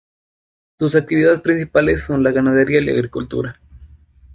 ga‧na‧de‧rí‧a
/ɡanadeˈɾia/